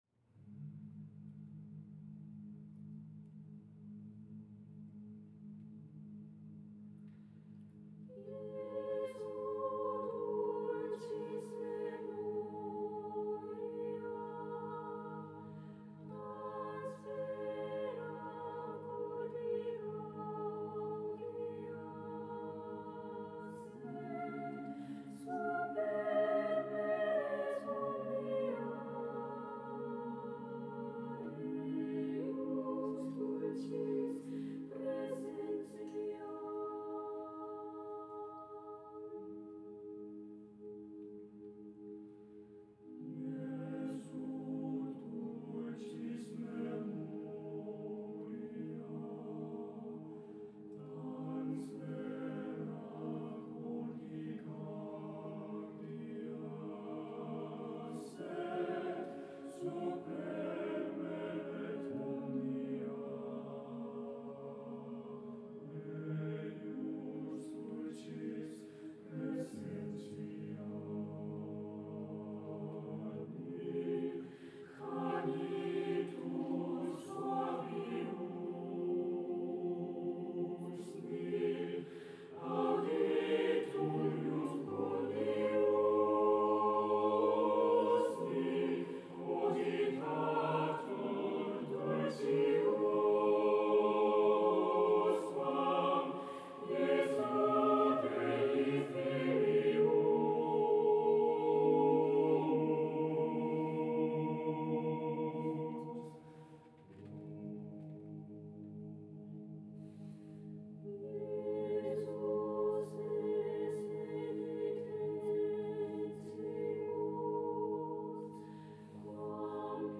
A Cappella , Choir , SATB